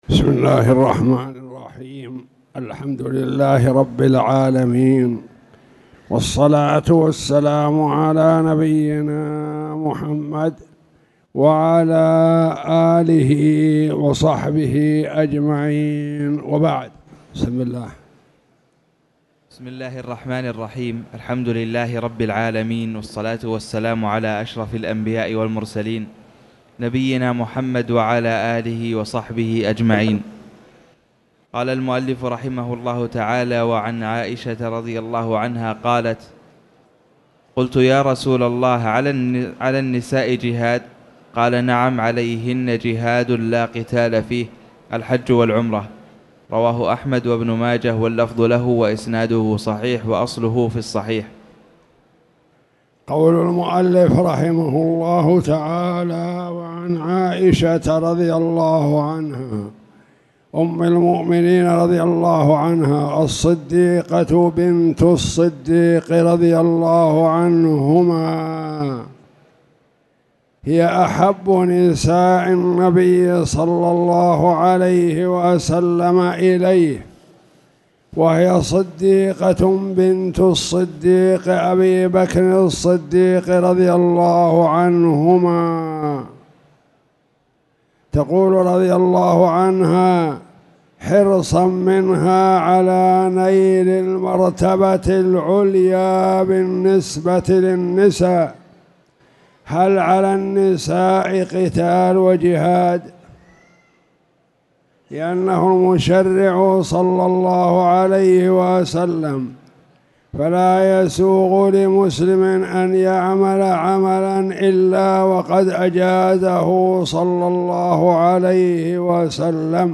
تاريخ النشر ٢٩ محرم ١٤٣٨ هـ المكان: المسجد الحرام الشيخ